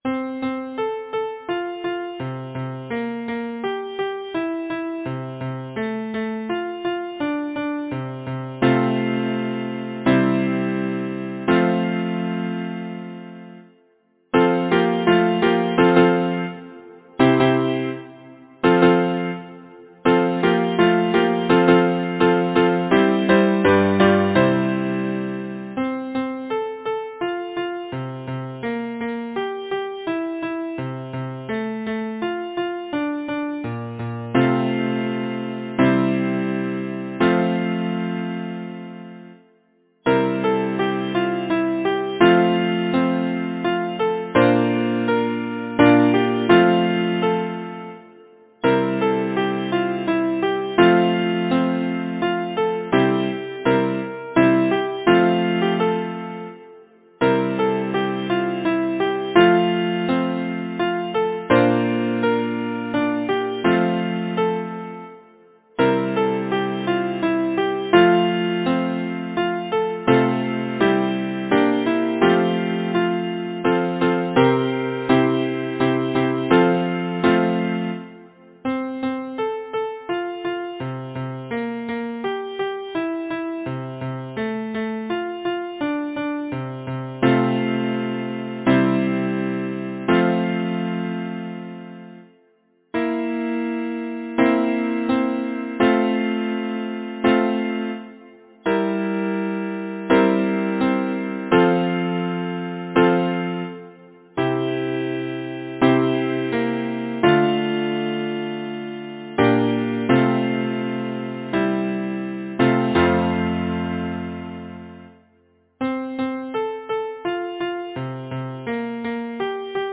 Title: Memory Bells Composer: Shilo Shaffer Myers Lyricist: Number of voices: 4vv Voicing: SATB Genre: Secular, Partsong
Language: English Instruments: A cappella